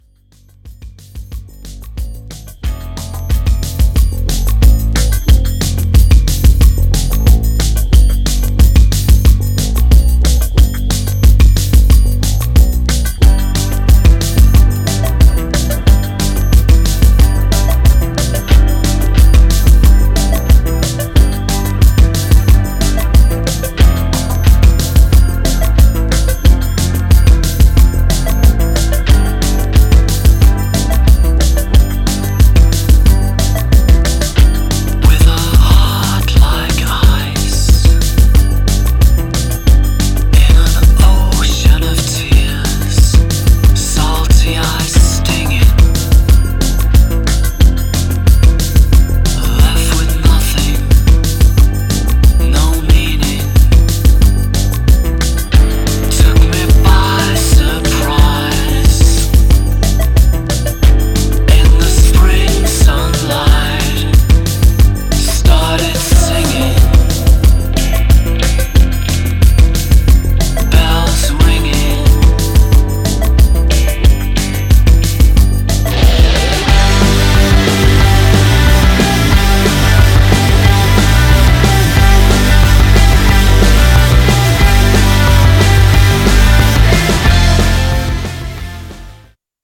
Styl: Dub/Dubstep, Drum'n'bass, Breaks/Breakbeat